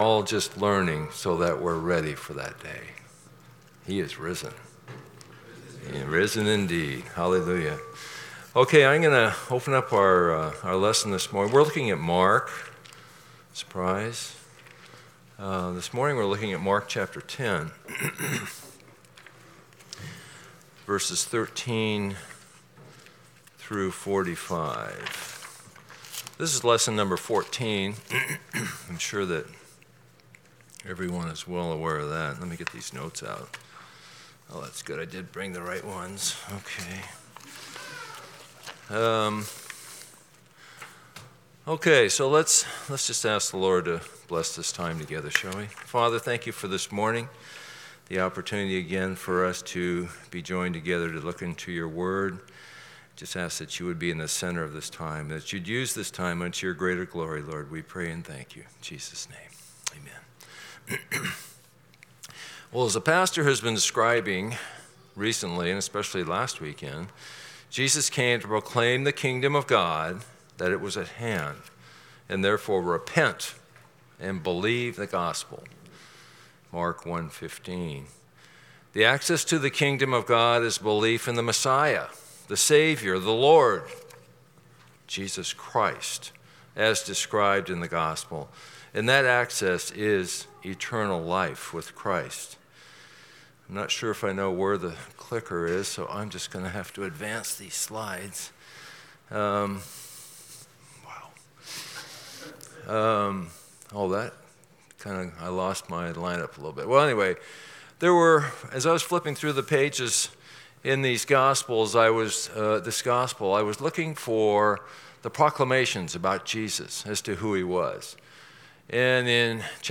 Passage: Mark 10:13-45 Service Type: Sunday School